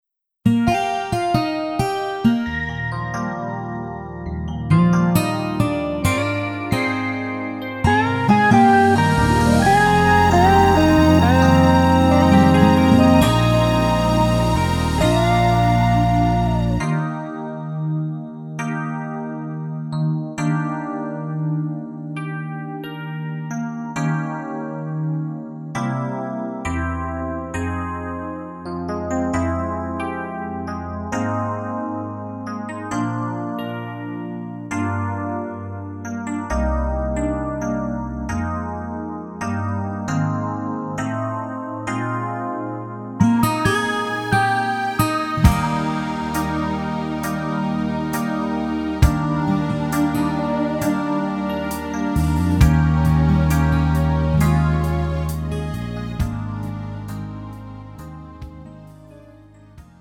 음정 원키 3:37
장르 가요 구분 Pro MR